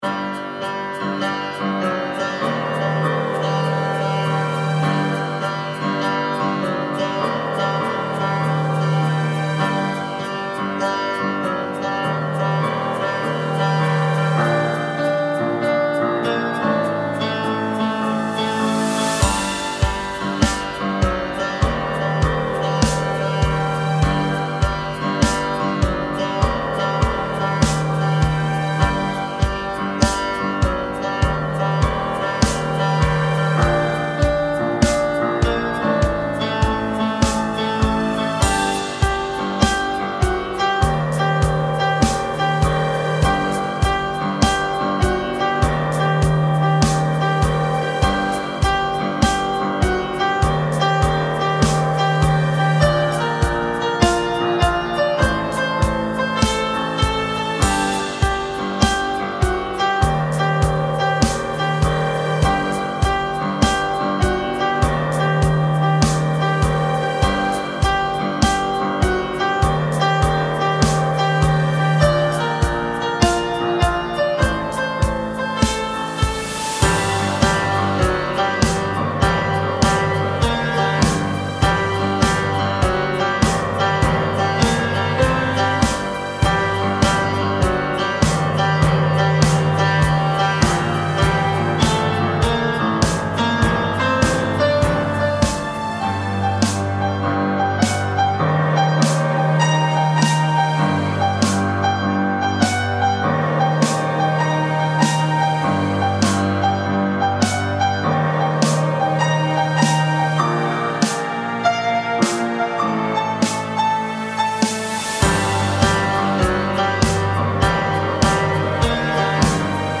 We recorded my housemate playing the piano, I took some of the best parts built a track around it. Sure there are some timing issues that I didn’t have enough time to fix, and looking back there might have been somethings I did differently, but ultimately I think it came out quite well.